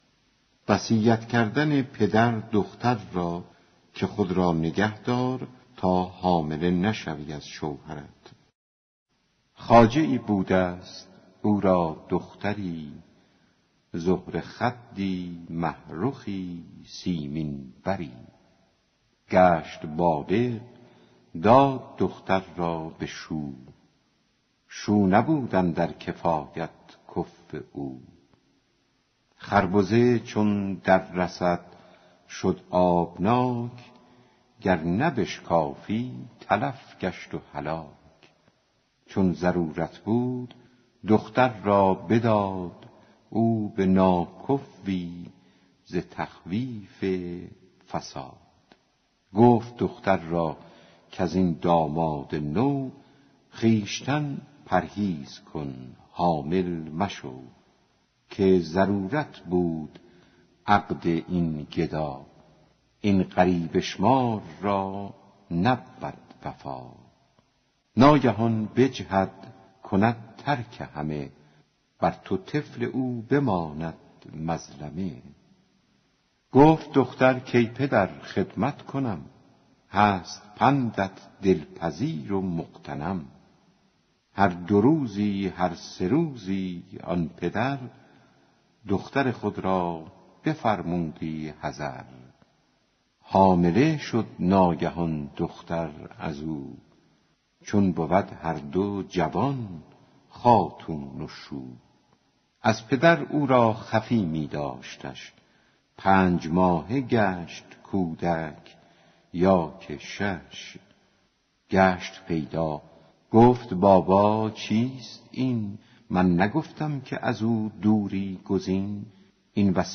دکلمه وصیت پدر به دخترش که خود را نگهدار تا حامله نشوی